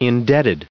423_indebted.ogg